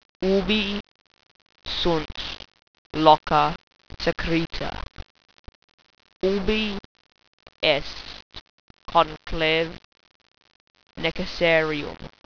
(the audio version is terrible)